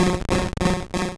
Cri de Krabby dans Pokémon Rouge et Bleu.